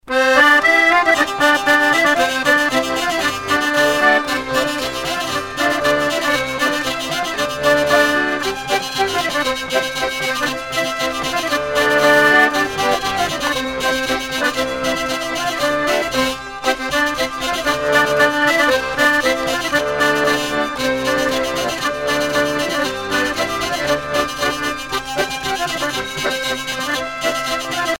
danse : chibreli
Pièce musicale éditée